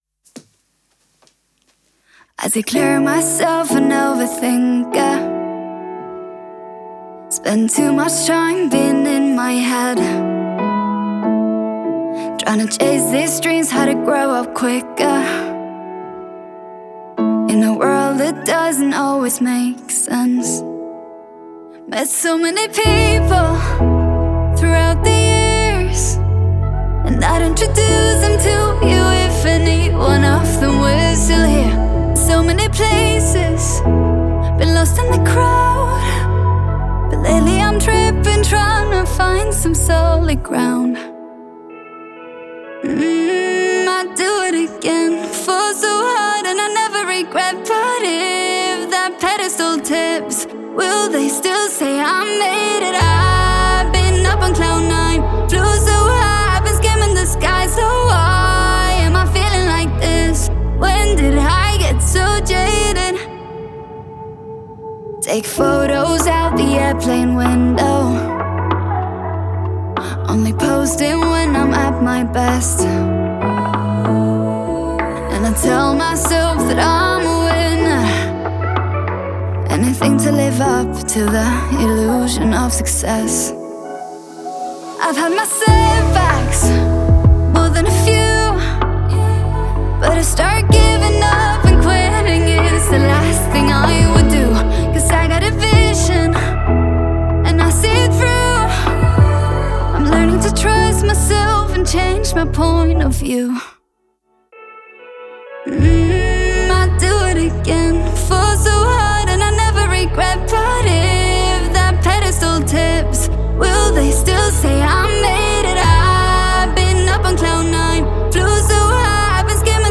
энергичная поп-песня норвежской певицы
Настроение песни — меланхоличное, но с искрой надежды.